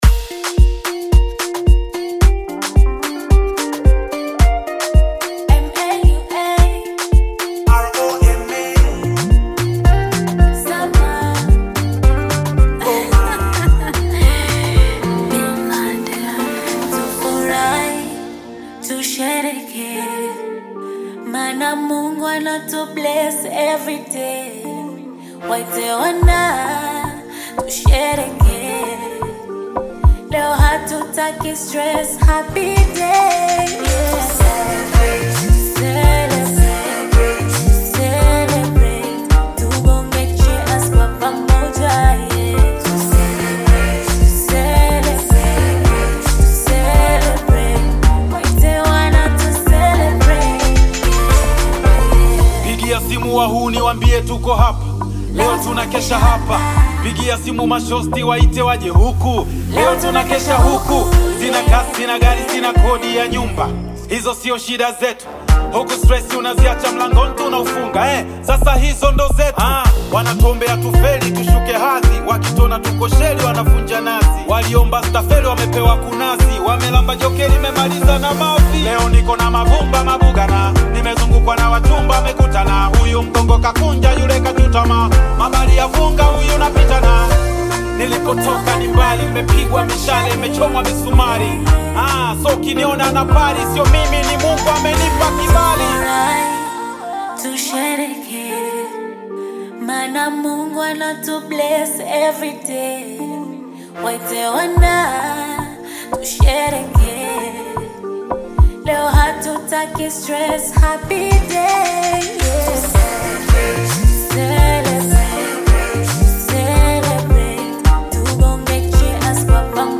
a lively and uplifting music audio